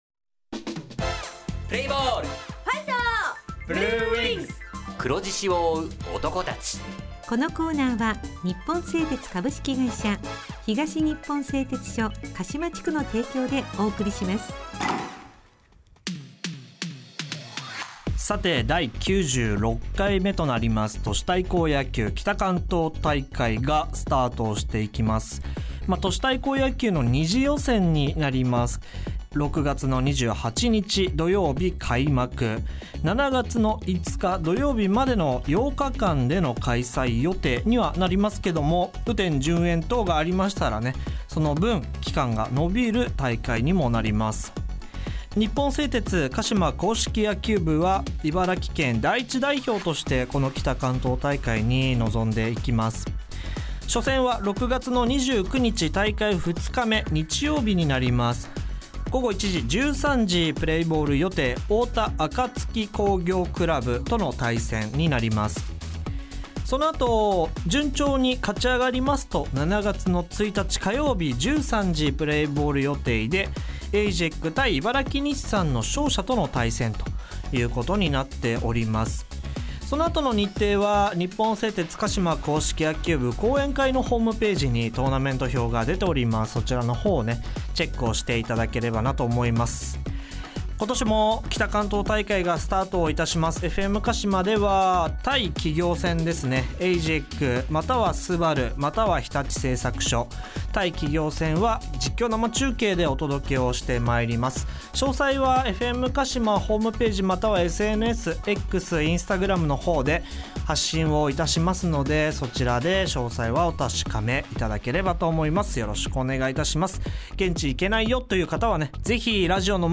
選手インタビュー
地元ＦＭ放送局「エフエムかしま」にて鹿島硬式野球部の番組放送しています。